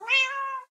cat
meow3.ogg